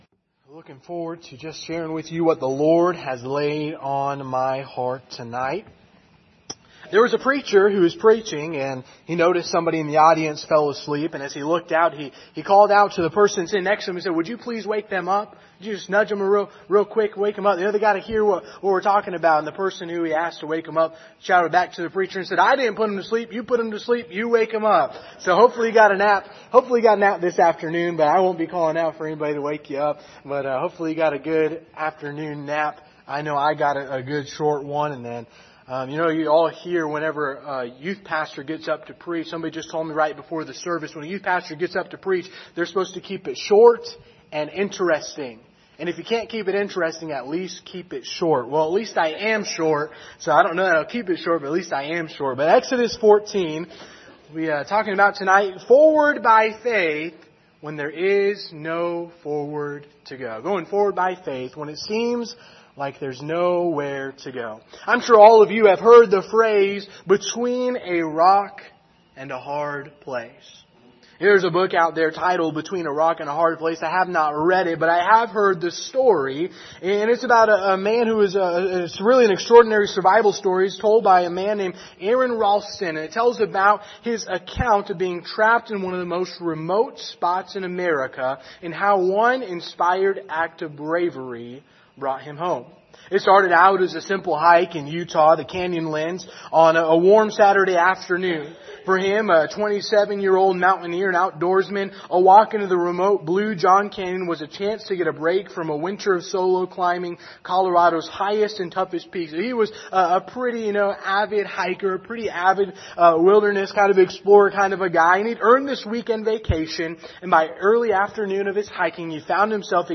Passage: Exodus 14:10-22 Service Type: Sunday Evening